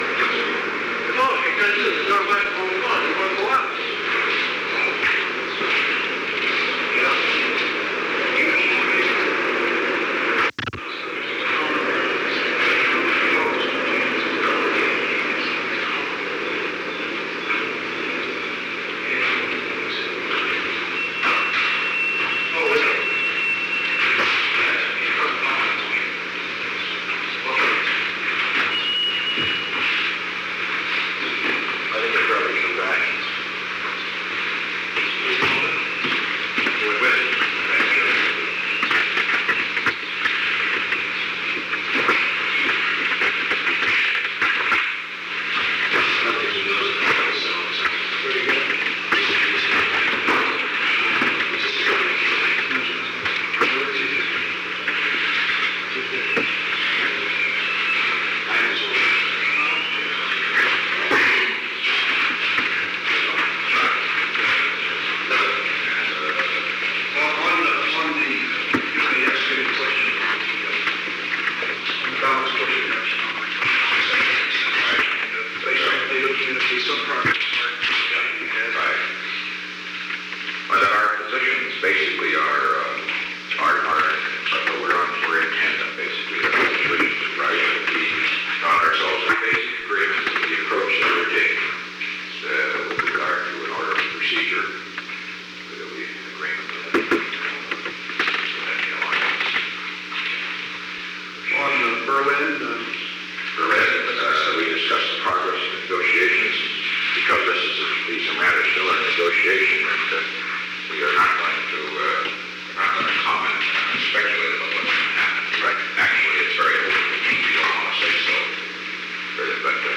Secret White House Tapes
Conversation No. 520-7
Location: Oval Office